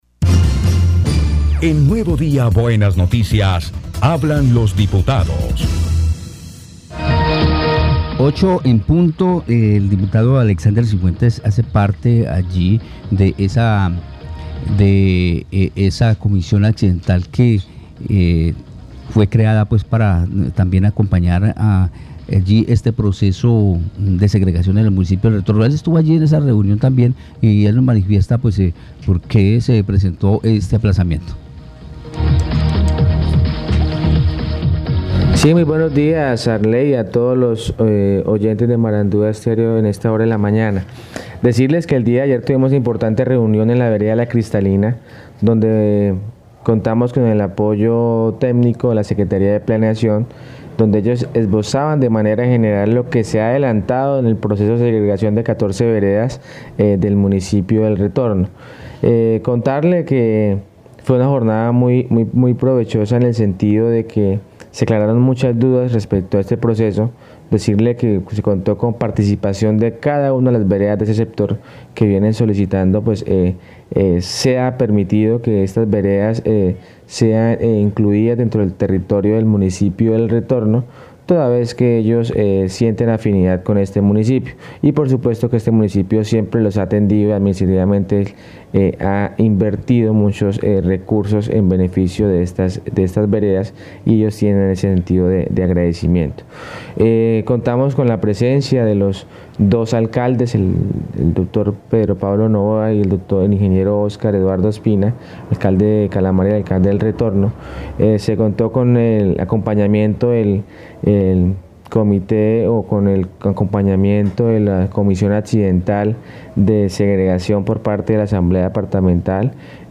Escuche a Oscar Ospina, alcalde de El Retorno, Guaviare.